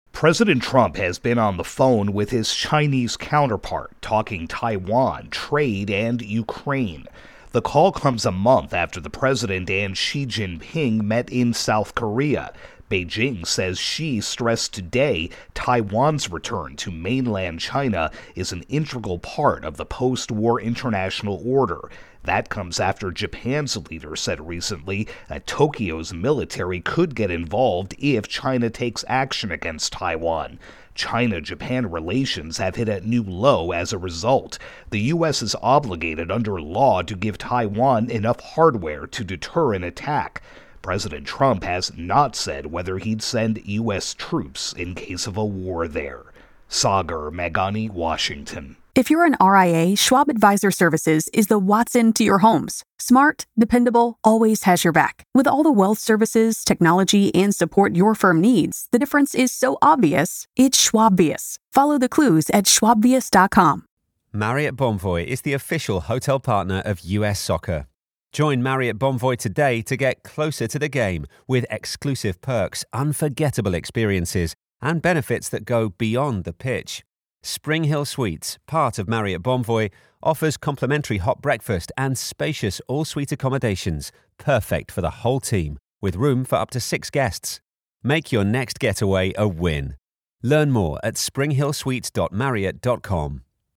reports on a call between President Trump and China's leader.